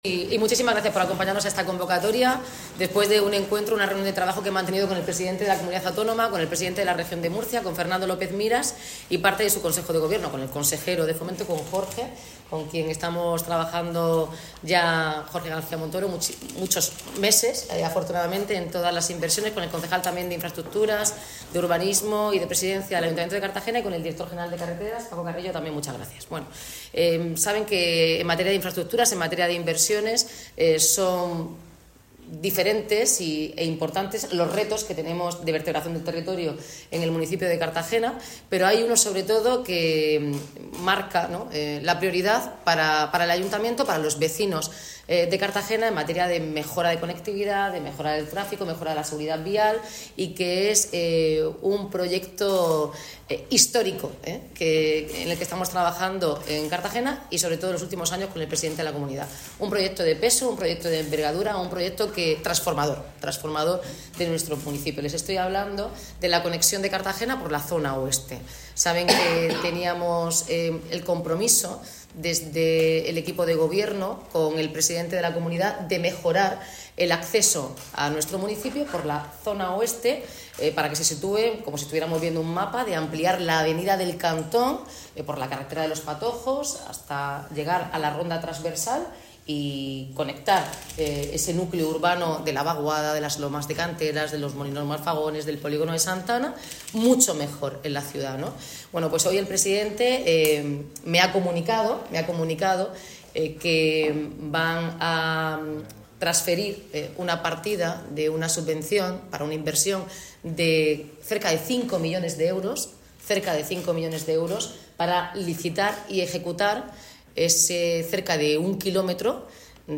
Enlace a Declaraciones de la alcaldesa, Noelia Arroyo, y el presidente autonómico Fernando López Miras.